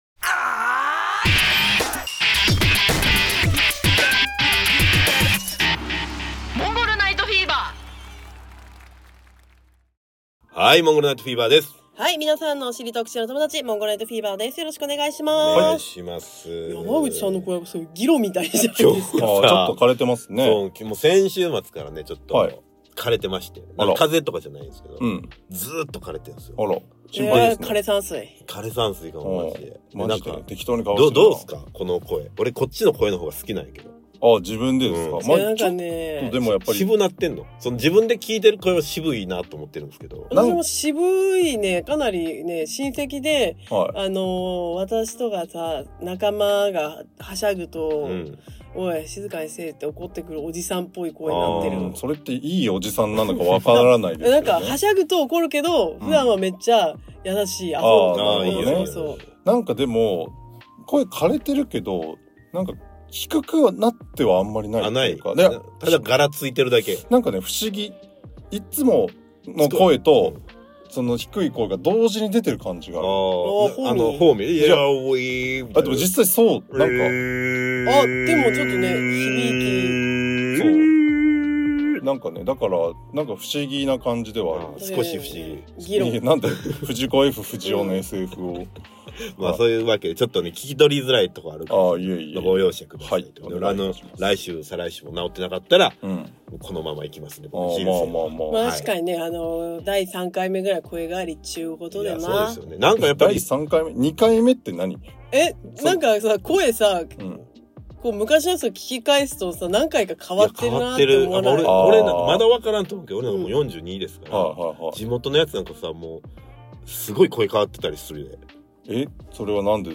・少し不思議な声